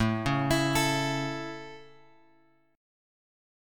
A chord {5 4 x x 5 5} chord
A-Major-A-5,4,x,x,5,5-8.m4a